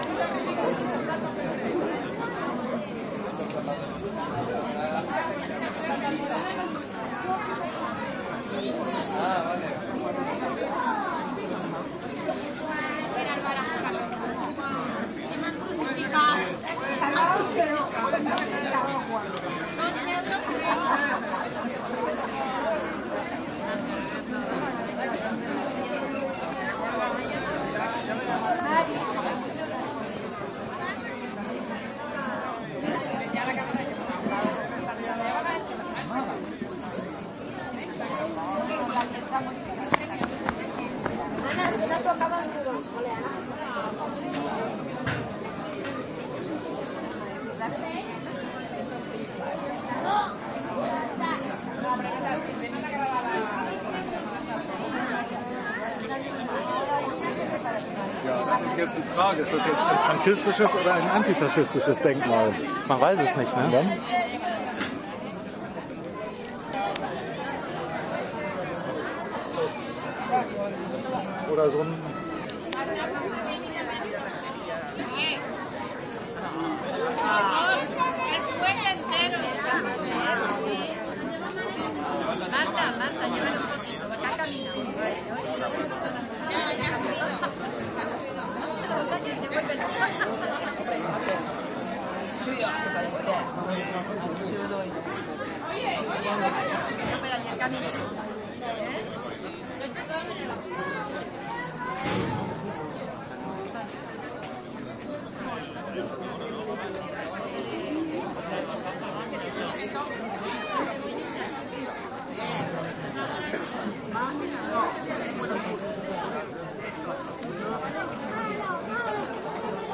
描述：僧侣在香港大屿山的宝琳寺内吟唱。
声道立体声